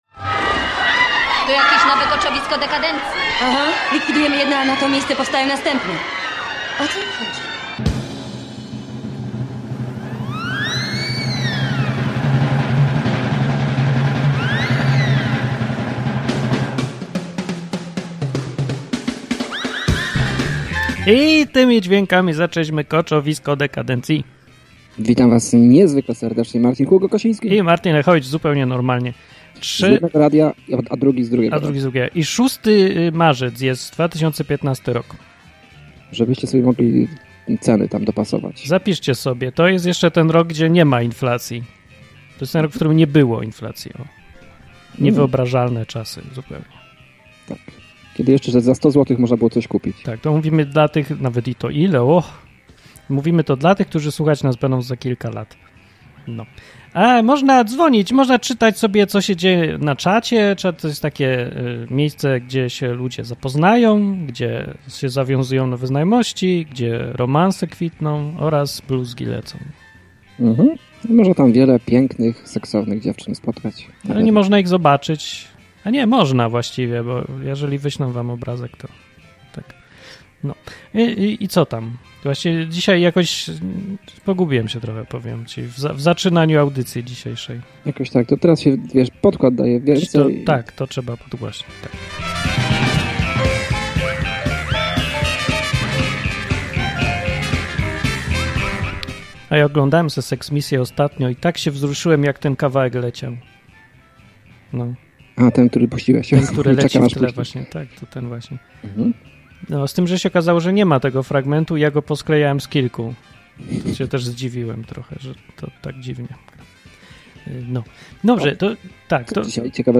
Koczowisko Dekandencji to dwu-radiowa audycja, w której od luzu, sarkazmu i ironii wióry lecą.